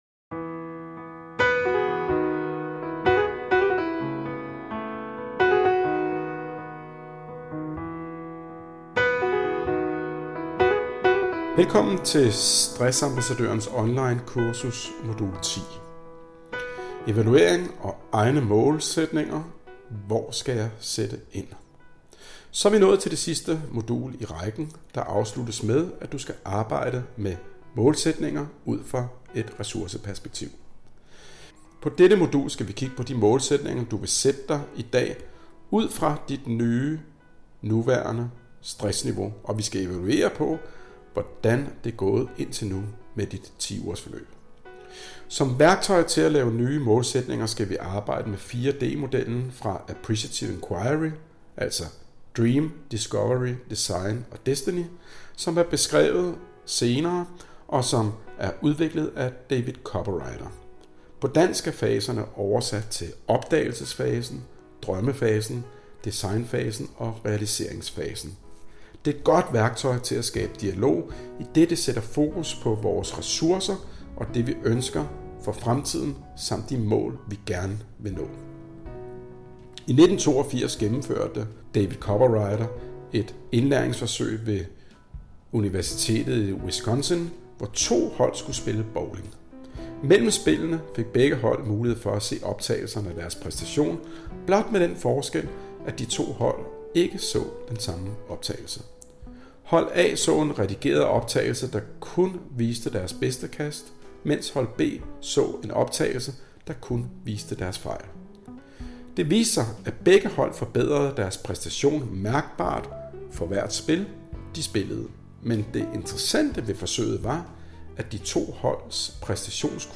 Indlaest-Artikel-Modul-10-Maalsaetninger-indtalt-tekst.mp3